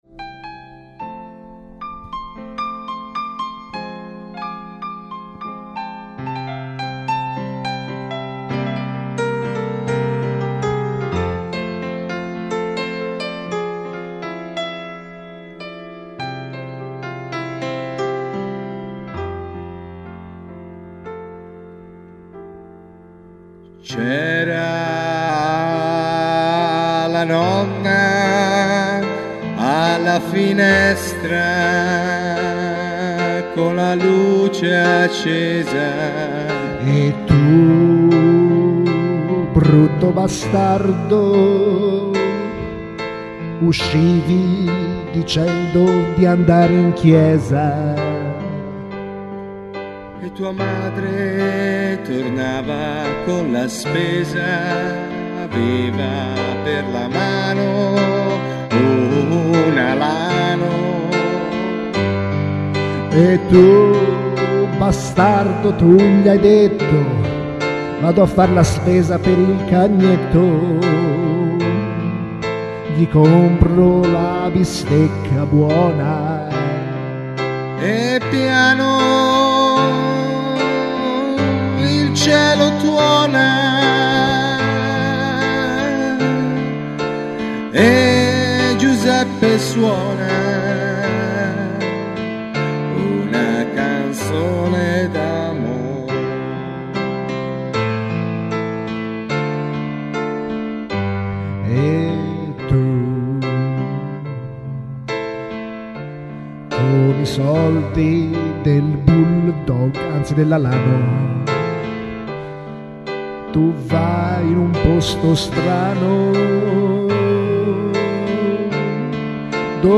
Un pezzo nonsense improvvisato